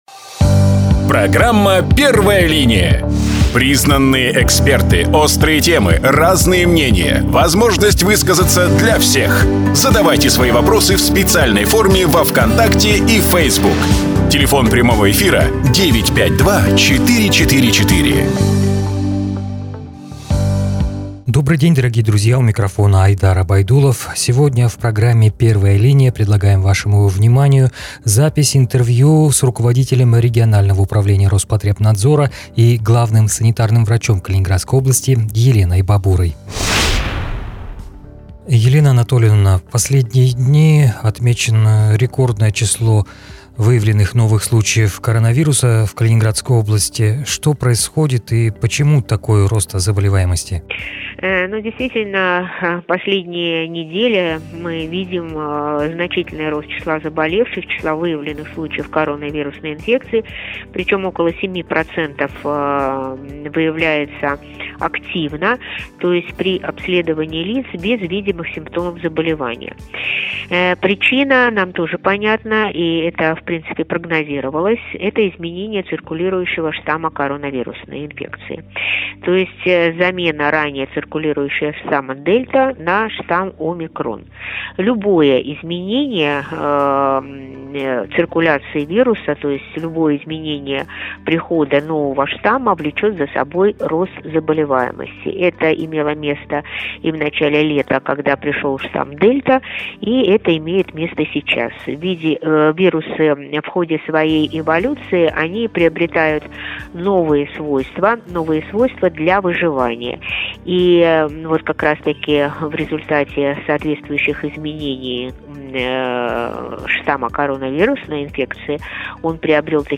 Об этом в интервью РИА «Балтик Плюс» сообщила руководитель регионального управления Роспотребнадзора Елена Бабура.